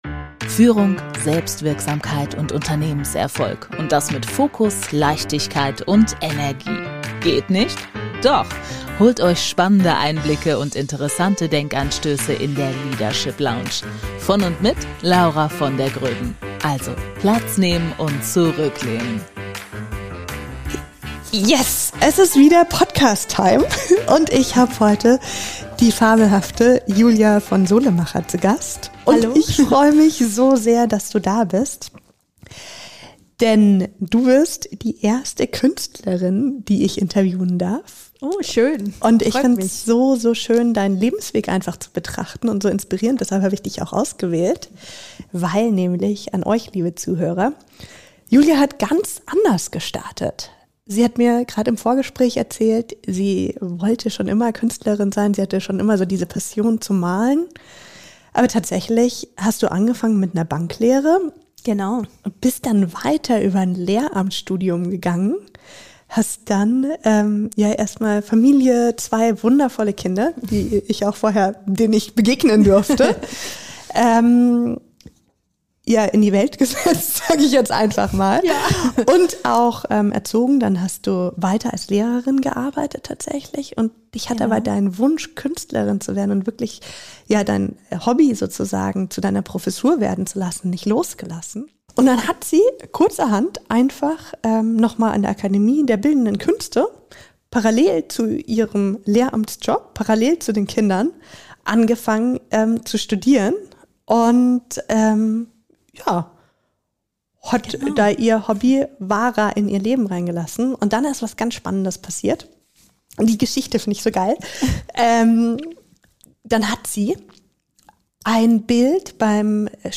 Was passiert, wenn du endlich deinem Bauchgefühl folgst? Interview